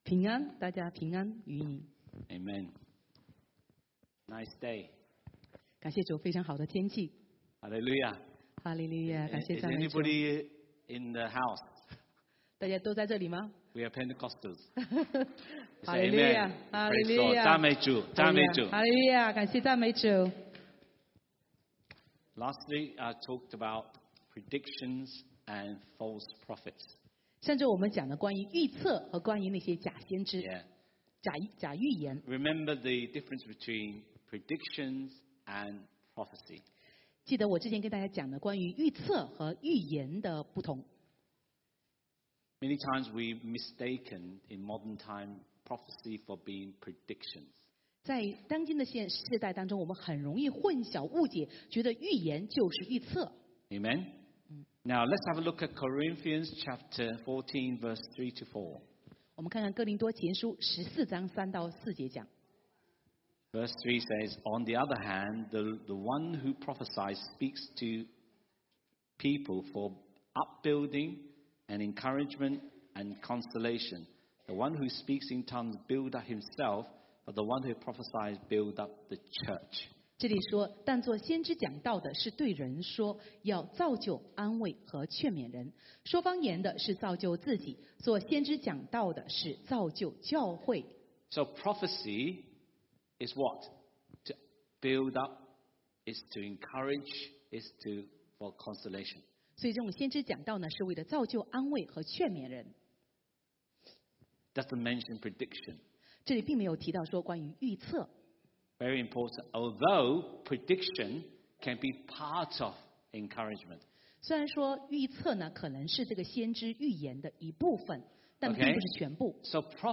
East Ham Church Sermon